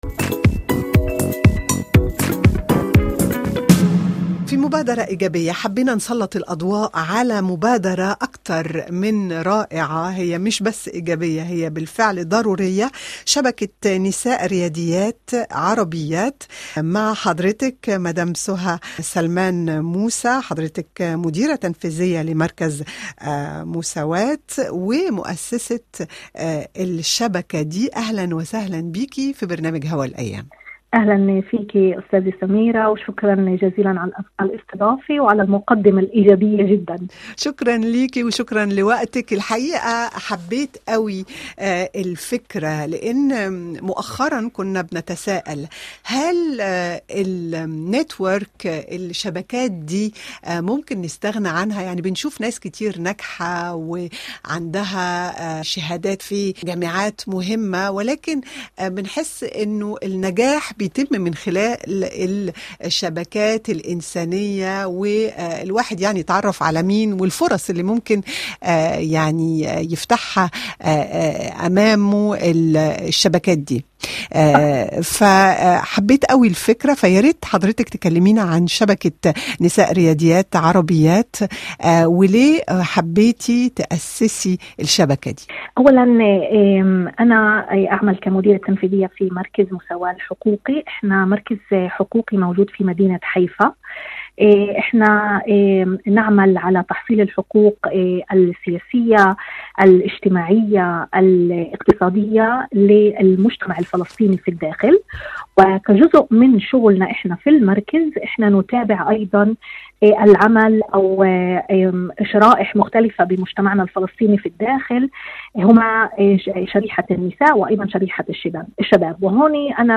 برنامج تفاعلي يحمل قيم الانفتاح وتعددية الأفكار والآراء كما يهتم بأهواء المستمعين والفاعلين على شبكات التواصل الاجتماعي. يأتيكم يومياً من ال4 حتى ال5 مساءً بتوقيت باريس.